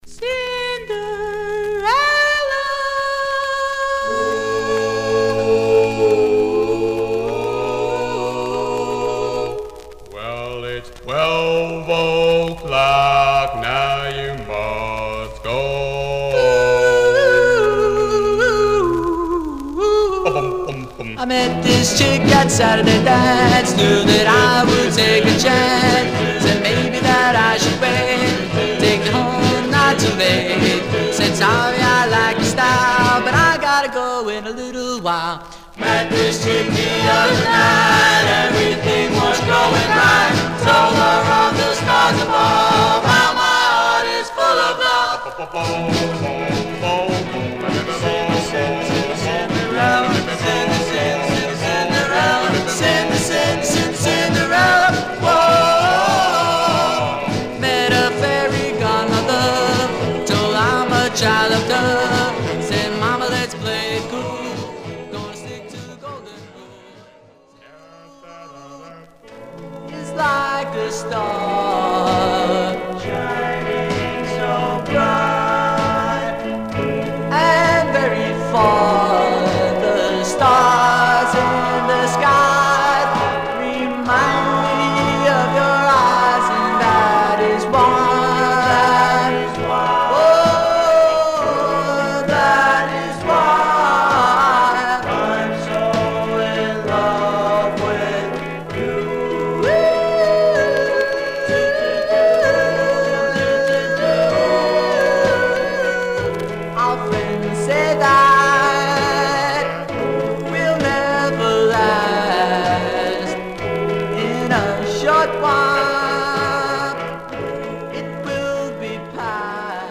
Stereo/mono Mono
Vocal Group